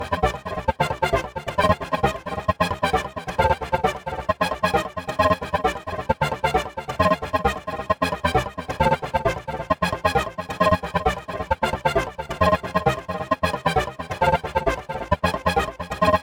• techno lead swing groove gated 2 - Cm.wav
techno_lead_swing_groove_gated_2_-_Cm_ys8.wav